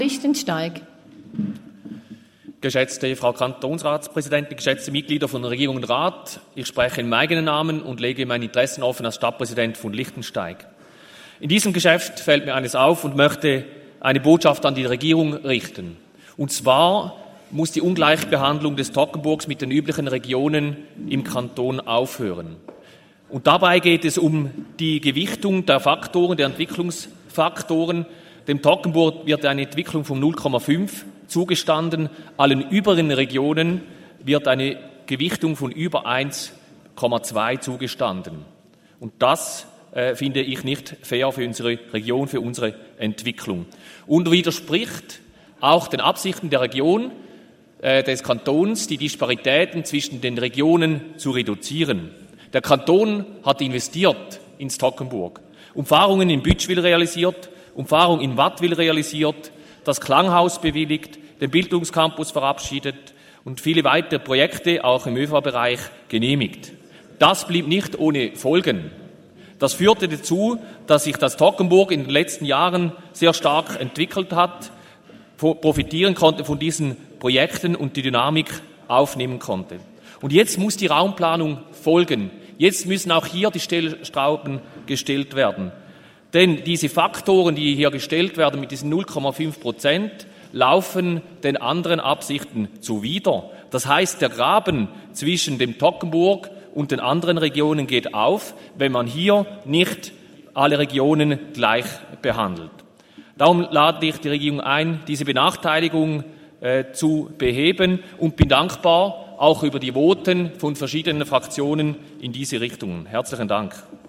Müller-Lichtensteig legt seine Interessen als Stadtpräsident von Lichtensteig offen.
Session des Kantonsrates vom 18. bis 20. September 2023, Herbstsession